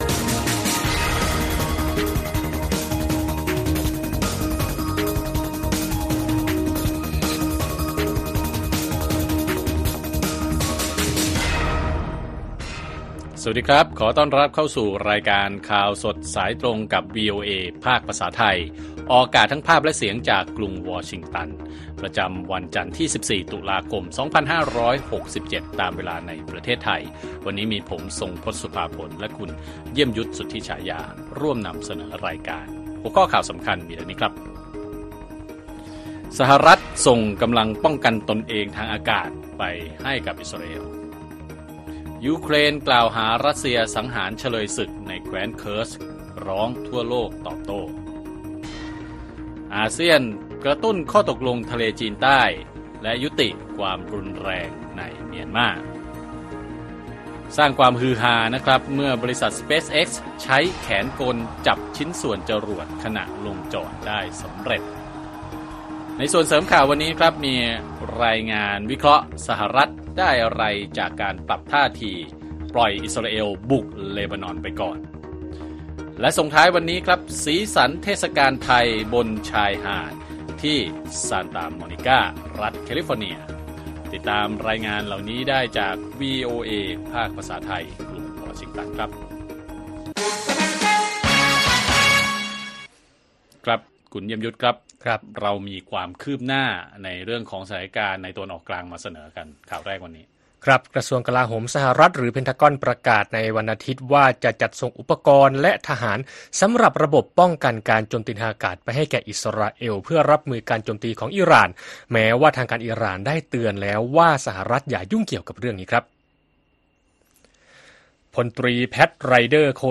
ข่าวสดสายตรงจากวีโอเอไทย วันจันทร์ ที่ 14 ตุลาคม 2567